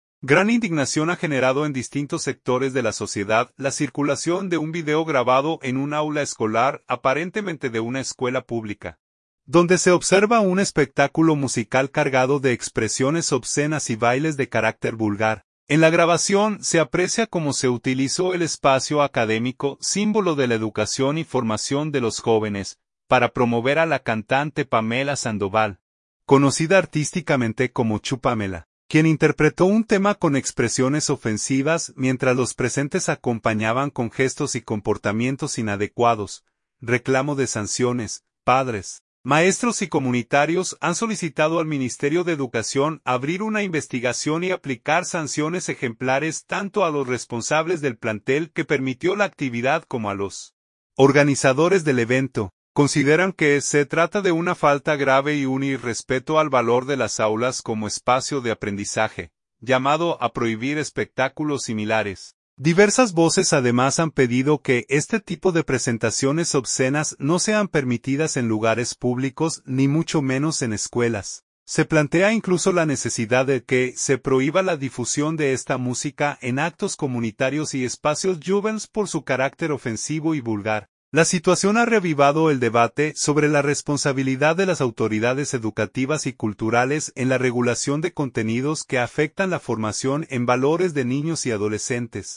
Gran indignación ha generado en distintos sectores de la sociedad la circulación de un video grabado en un aula escolar, aparentemente de una escuela pública, donde se observa un espectáculo musical cargado de expresiones obscenas y bailes de carácter vulgar.